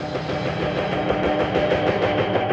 RI_DelayStack_95-06.wav